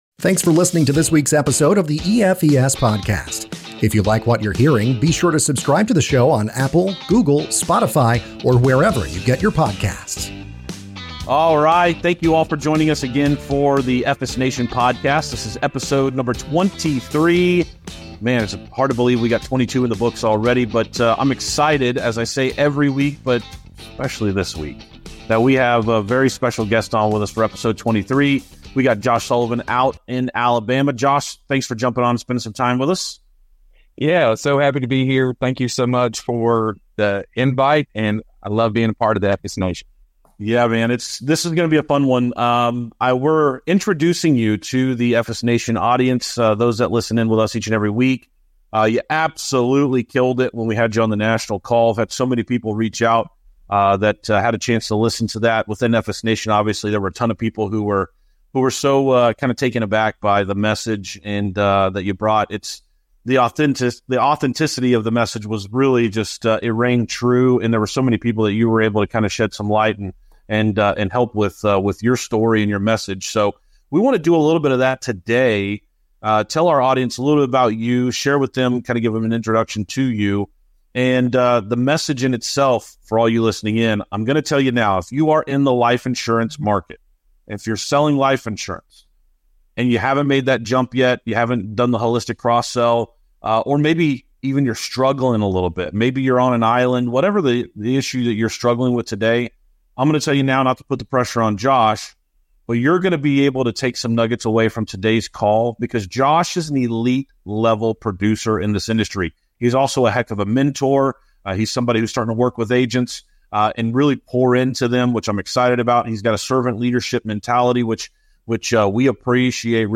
The Good, The Bad & The Ugly - Interview